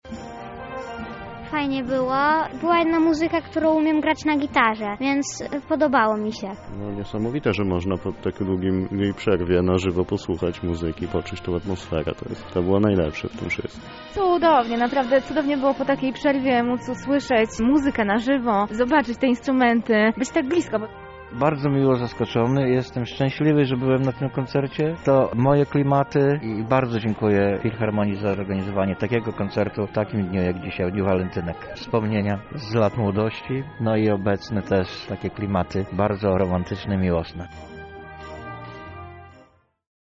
Uczestnicy podzielili się swoimi odczuciami.